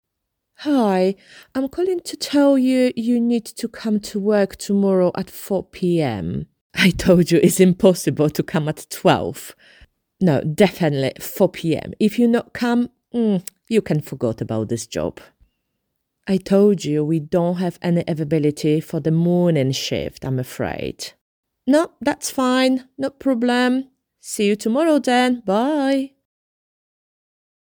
Kobieta 30-50 lat
Nagranie lektorskie w języku angielskim